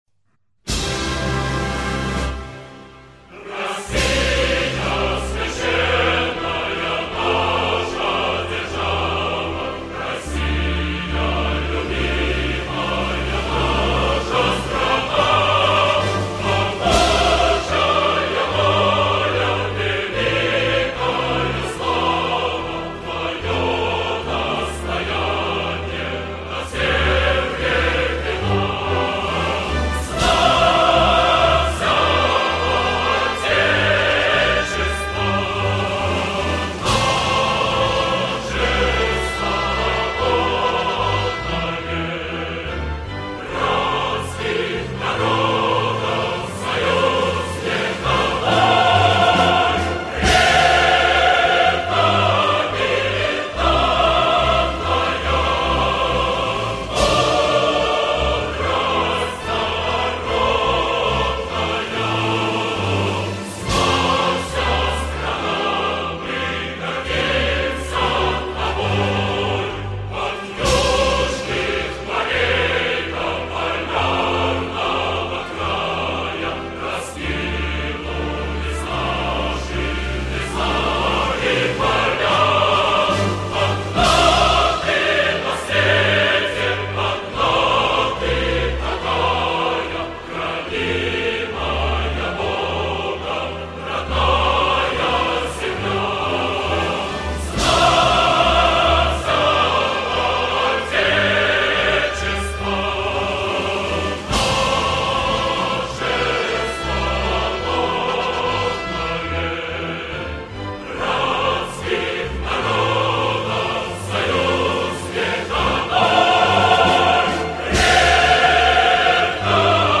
со словами